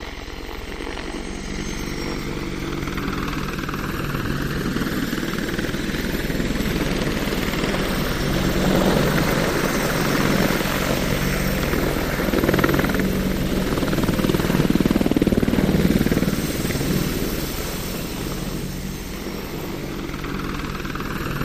AH-64 Apache | Sneak On The Lot
Apache Helicopter By Medium, Fast, Left, Right, Wind Past By Peak